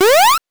jump_14.wav